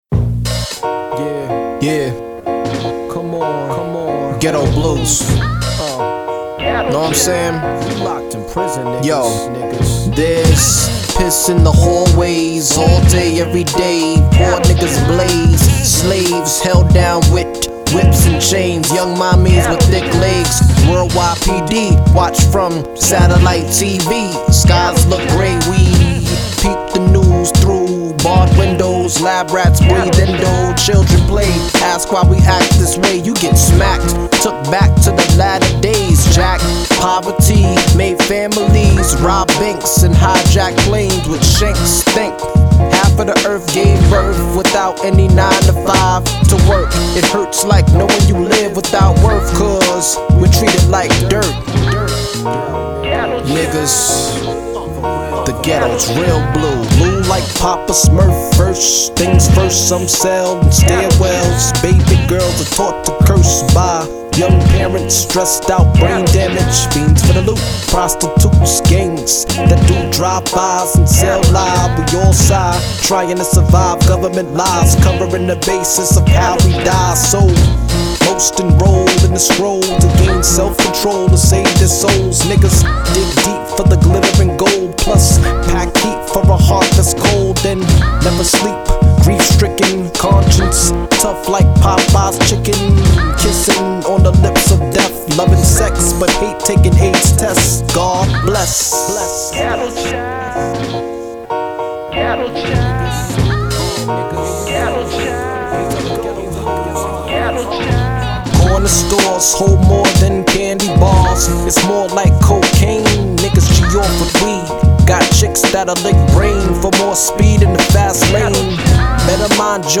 honest, gritty, and raw